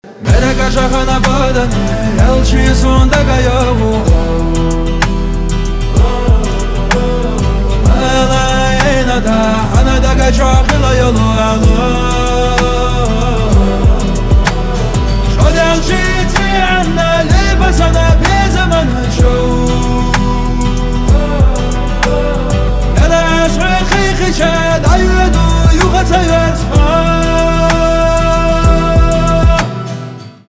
Красивые и чувственные битовые мелодии для вашего звонка.
поп гитара